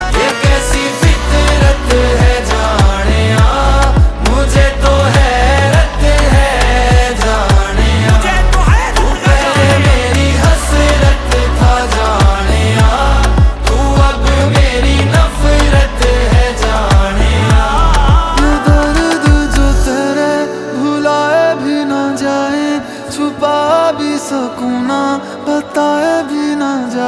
emotional and soulful tune